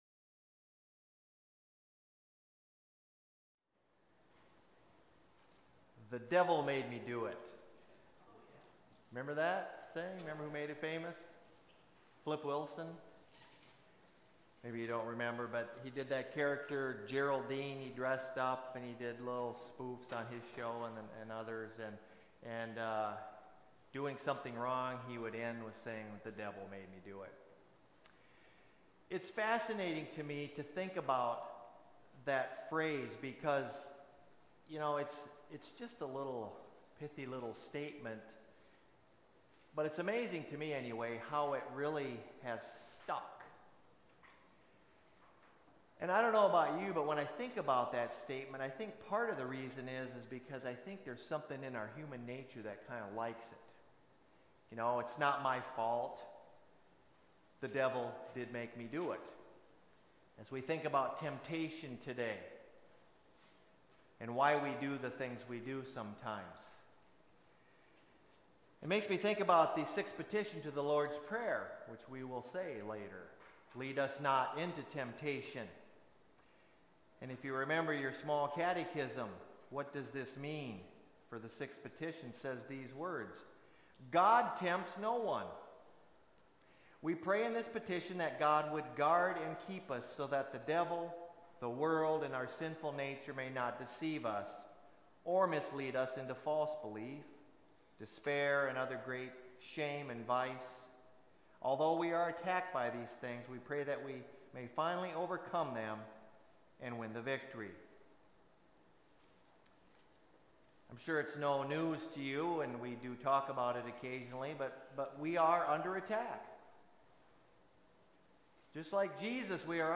Sermon on the Mount – Temptation